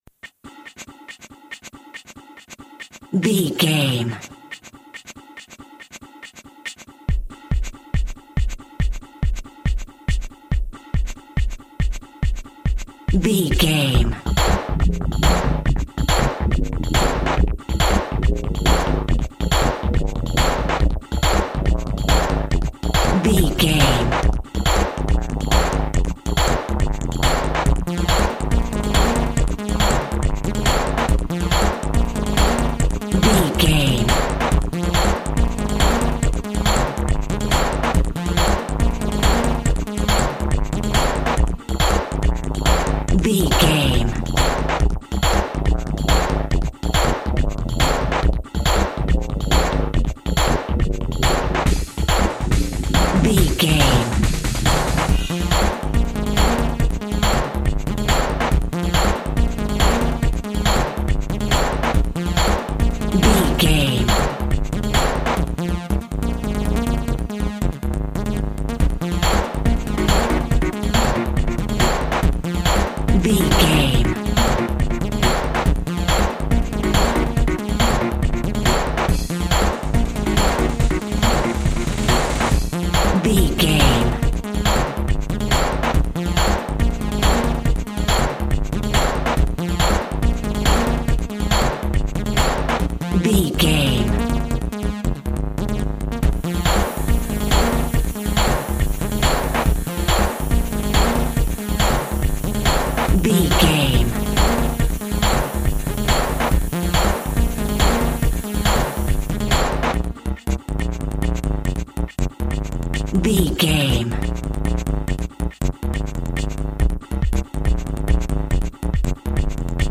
Aeolian/Minor
frantic
energetic
dark
hypnotic
mechanical
drum machine
synthesiser
percussion
forceful
synth lead
synth bass